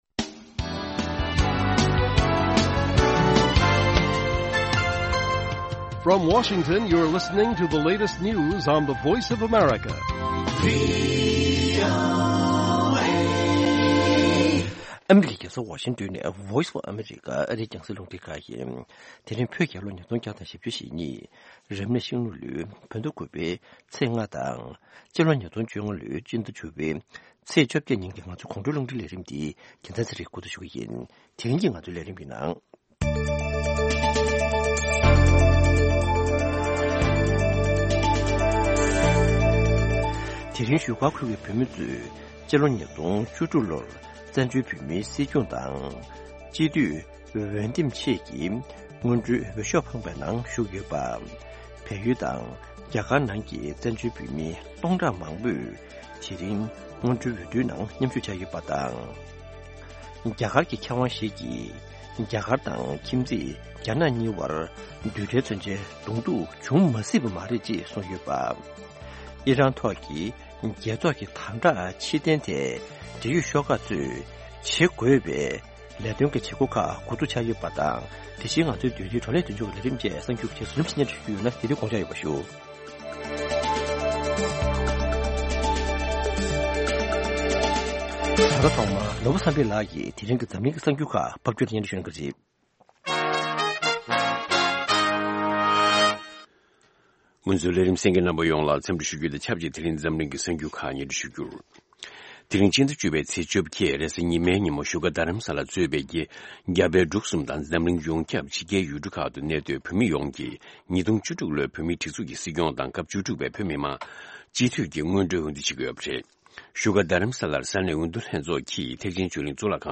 Evening News Broadcast daily at 10:00 PM Tibet time, the Evening Show presents the latest regional and world news, correspondent reports, and interviews with various newsmakers and on location informants. Weekly features include Tibetan Current Affairs, Youth, Health, Buddhism and Culture, and shows on traditional and contemporary Tibetan music.